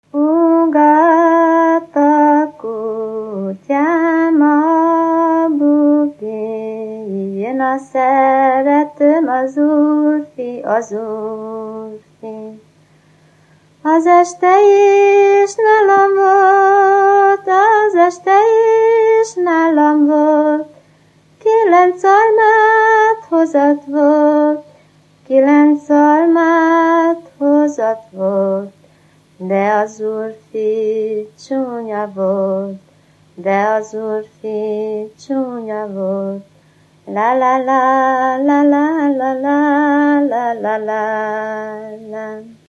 Erdély - Szolnok-Doboka vm. - Ördöngösfüzes
ének
Műfaj: Lassú cigánytánc
Stílus: 3. Pszalmodizáló stílusú dallamok
Kadencia: 4 (b3) b3 1